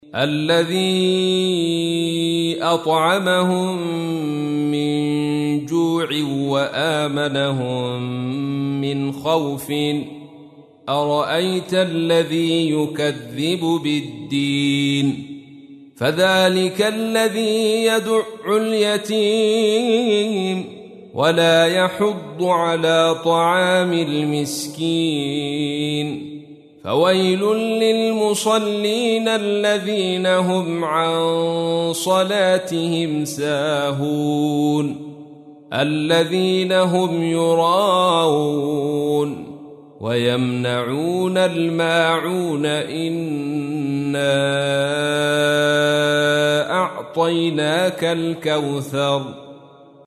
تحميل : 107. سورة الماعون / القارئ عبد الرشيد صوفي / القرآن الكريم / موقع يا حسين